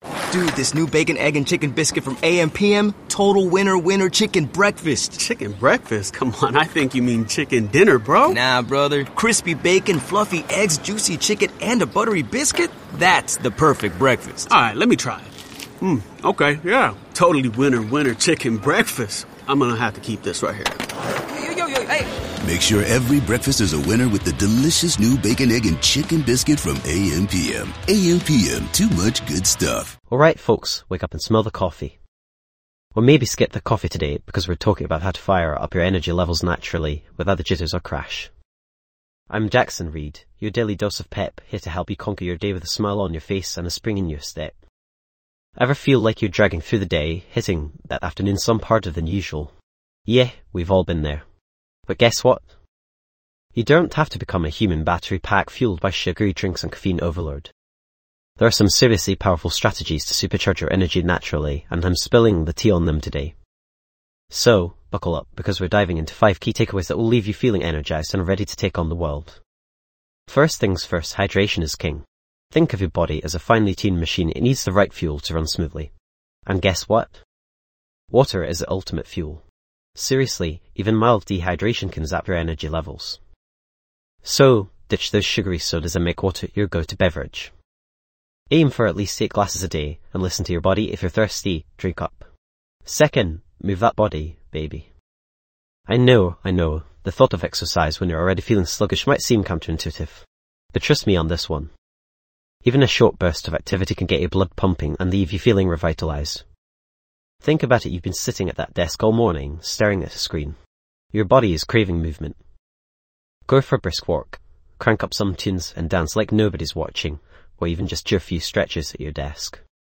Kickstart your day with a powerful pep talk on boosting your energy levels. Discover practical tips and strategies to increase your vitality and overcome daily slumps.
This podcast is created with the help of advanced AI to deliver thoughtful affirmations and positive messages just for you.